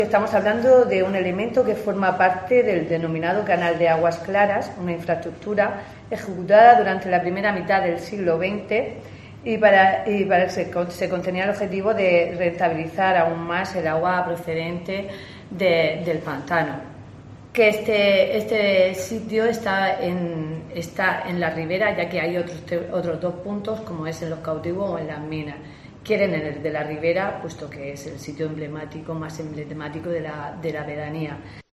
María Huertas García, concejal del PP